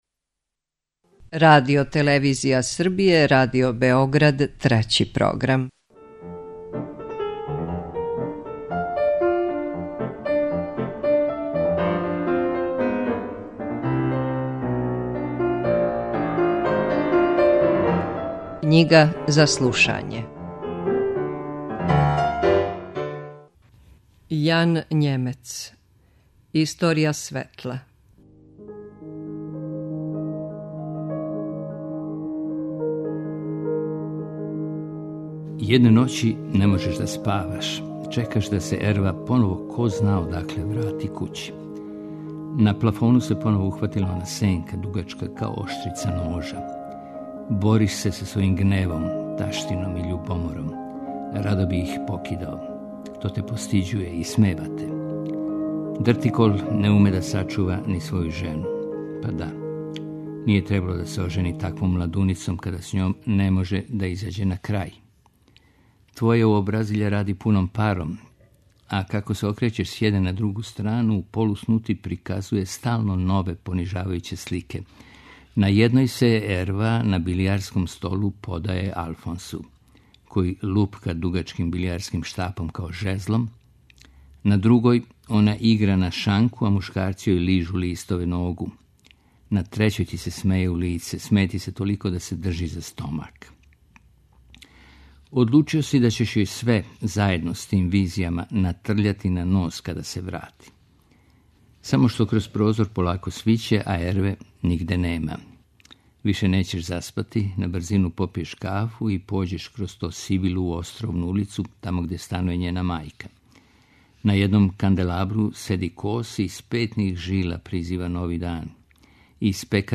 Knjiga za slušanje